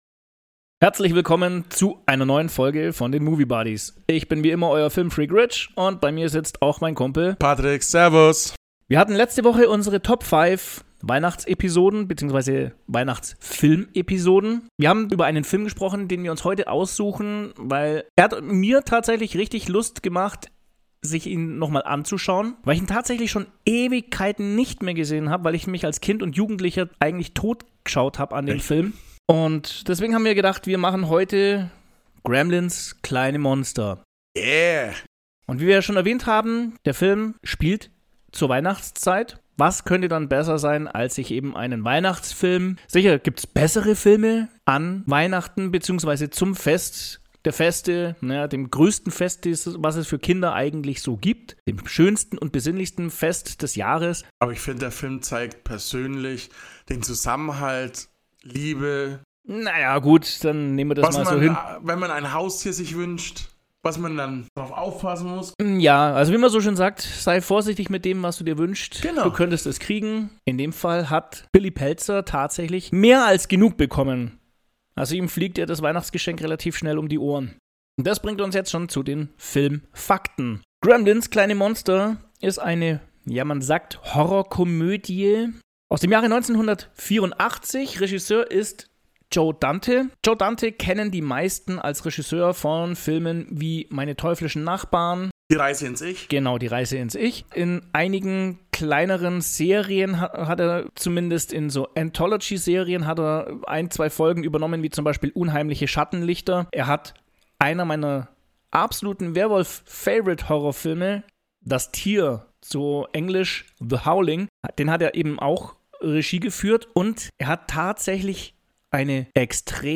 Nehmt Teil an einer umfangreichen Diskussion über Haustiere und die Verantwortung, die mit ihnen einhergeht, wie die Produktion umgestellt wurde und der jetzige Regisseur an seinen Posten kam.